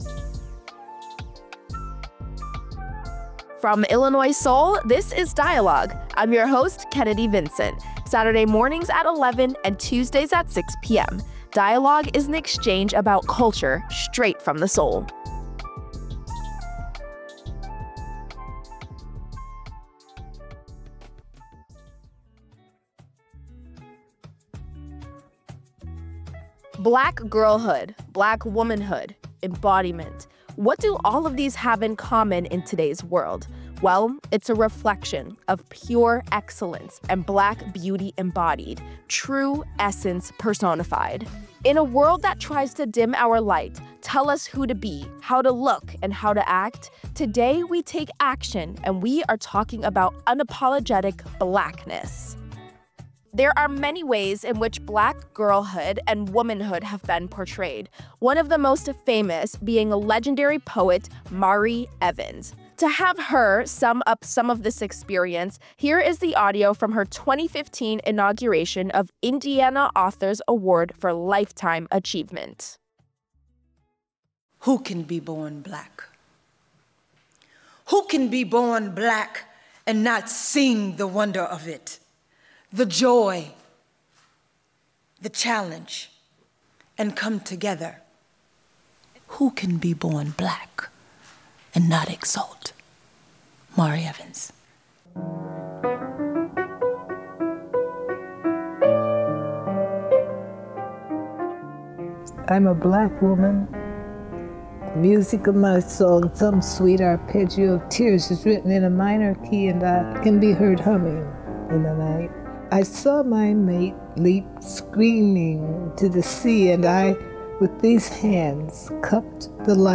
This week on "Dialogue," we talk with a scholar about how Black women and girls use beauty to empower themselves and push back against the world’s expectations. She shares what her research uncovered about how beauty connects to identity.